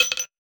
weapon_ammo_drop_25.wav